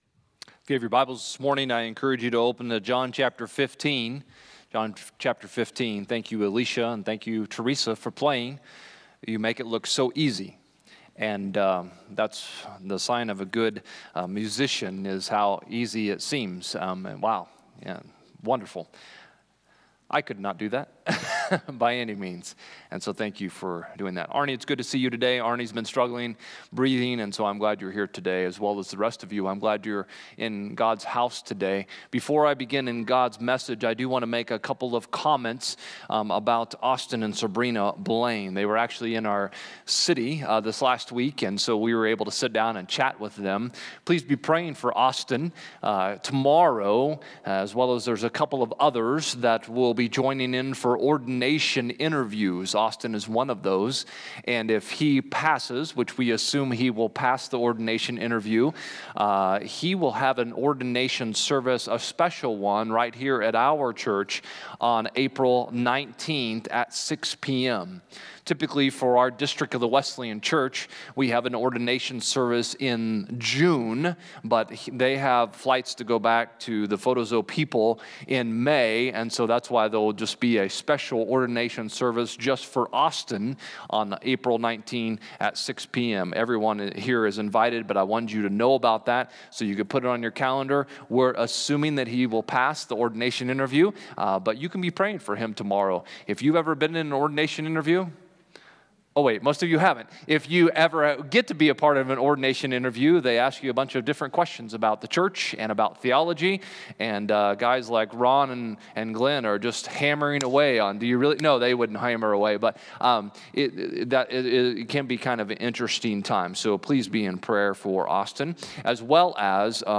Click Here to Follow Along with the Sermon on the YouVersion Bible App Romans 1:26-27 English Standard Version 26 For this reason God gave them up to dishonorable passions.